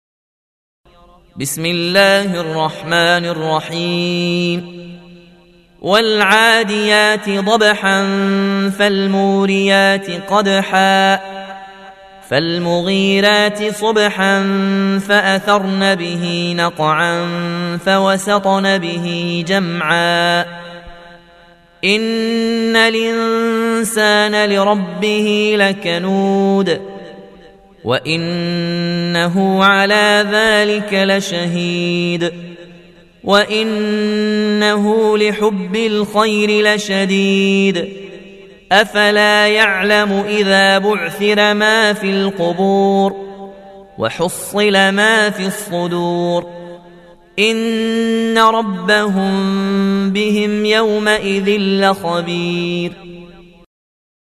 Riwayat Warsh an Nafi